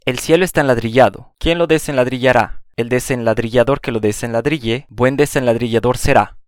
Pronúncia de “ll” em espanhol
A pronúncia que você acabou de ouvir é a mexicana.